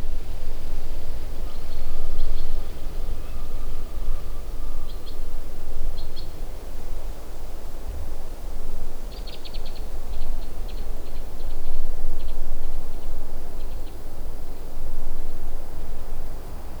White-winged Crossbill
Summerhill (Salt Rd. near Dresser Rd.), 13 August 2008
Presumably two birds in flight